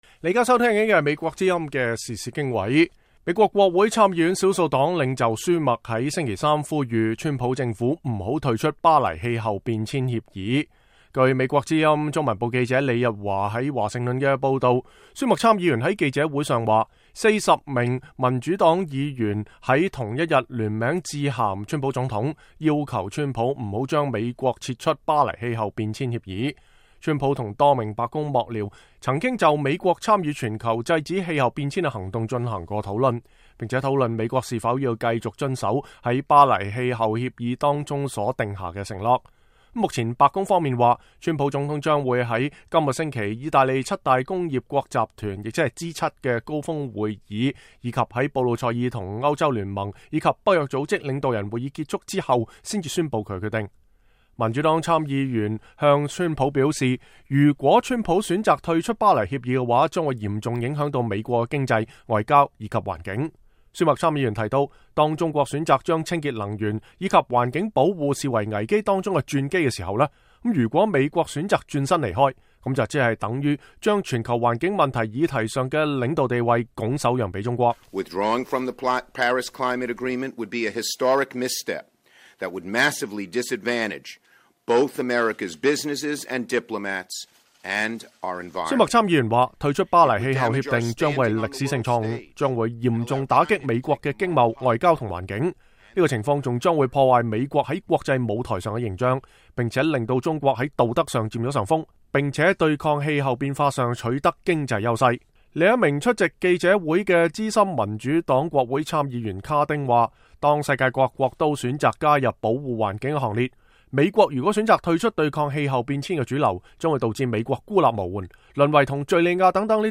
舒默在記者會上說，40位民主黨參議員在同一天聯名致信總統川普，要求川普不要將美國撤出《巴黎氣候變遷協議》。